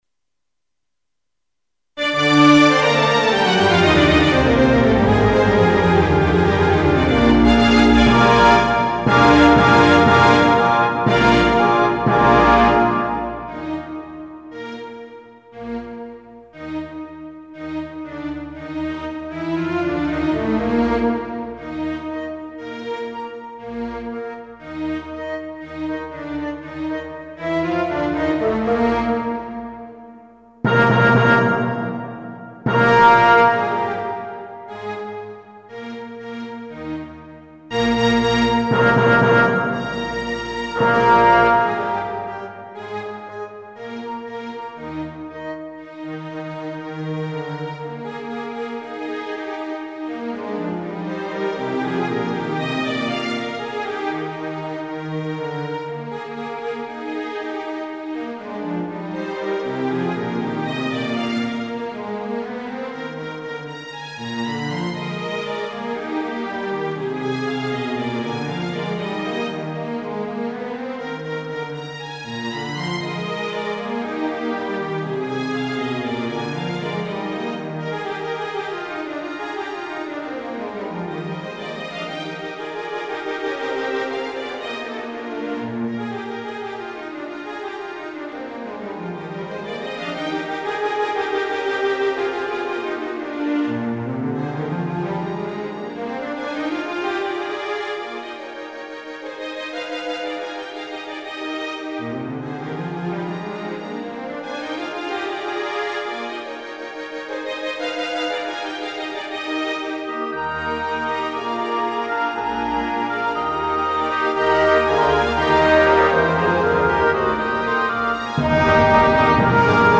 Made with "Miroslav Philharonik"
CLASSICAL MUSIC ; SYMPHONIC MUSIC